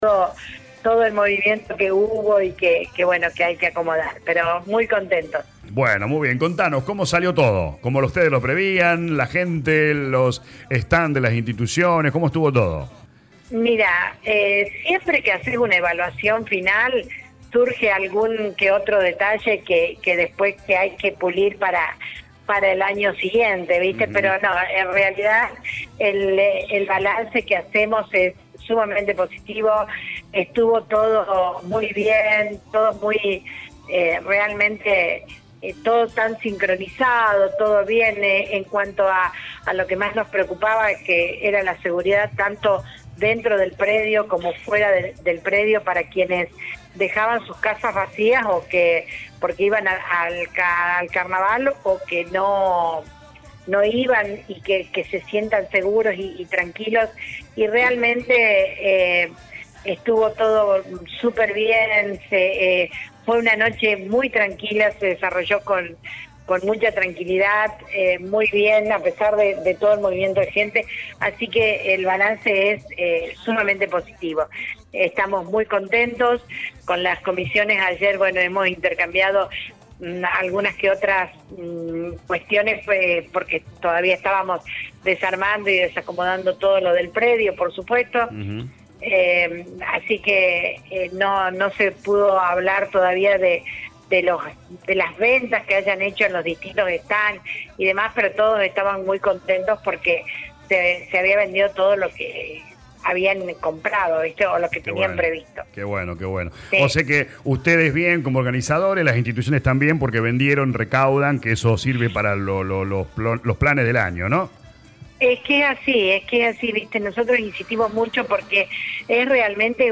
La intendente de la localidad de Porteña Nora Passero dialogó con LA RADIO 102.9 FM e hizo un balance de la «Fiesta del Pueblo» que contó con una gran convocatoria y rotundo apoyo del público local y de la zona.